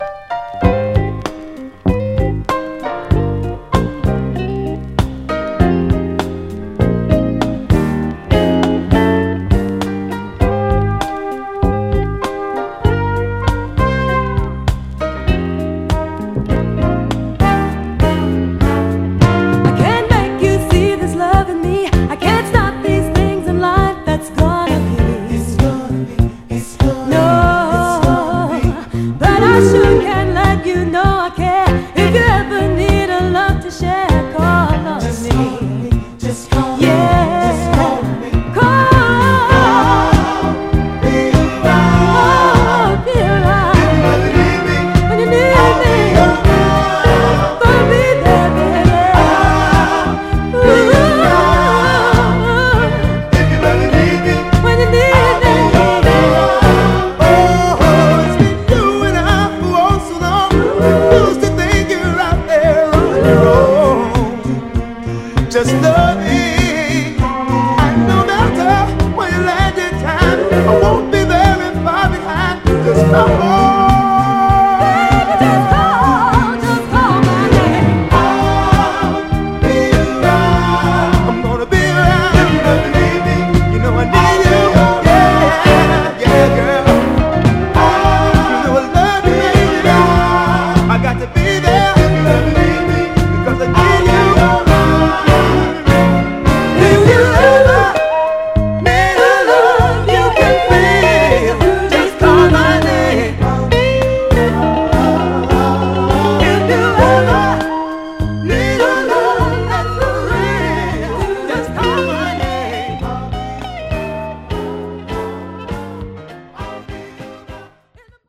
文句なしに美しいメロウ・ステッパー〜ミッド・ダンサーなモダン・ソウルの人気タイトルです！
盤はエッジ中心にごく細かいスレ、ごく薄い線スレ箇所ありますが、グロスが残っておりプレイ良好です。
※試聴音源は実際にお送りする商品から録音したものです※